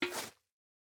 Minecraft Version Minecraft Version 25w18a Latest Release | Latest Snapshot 25w18a / assets / minecraft / sounds / item / bucket / fill_powder_snow2.ogg Compare With Compare With Latest Release | Latest Snapshot
fill_powder_snow2.ogg